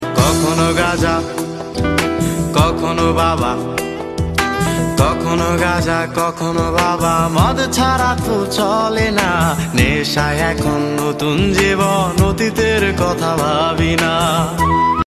You Just Search Sound Effects And Download. tiktok funny sound hahaha Download Sound Effect Home